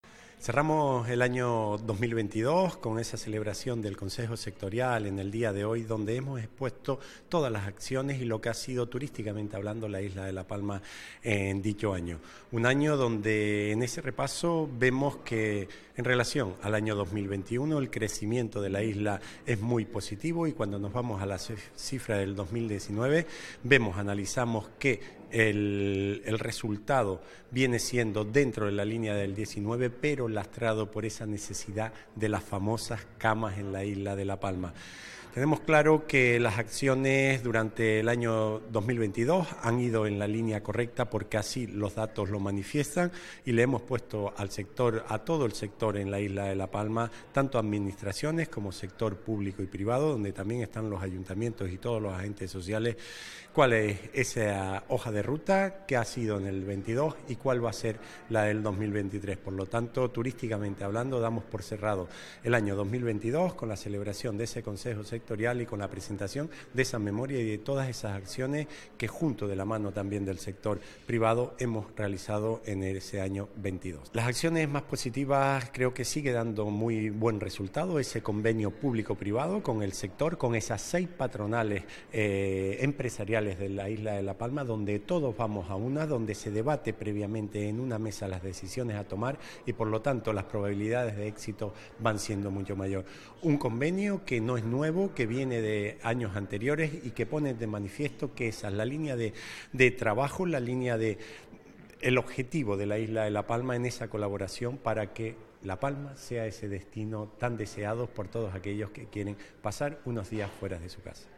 Declaraciones_audio_Raúl_Camacho_Consejo_Sectorial_Turismo.mp3